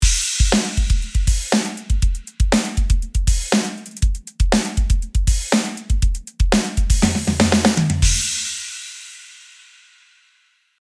シンプルなドラムフレーズを用意しました。
replikaxt_dry.mp3